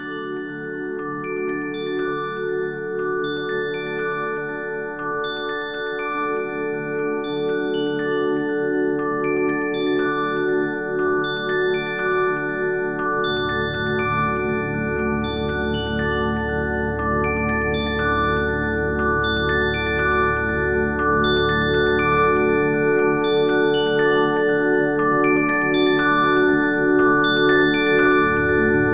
sony-xperia-blips_24608.mp3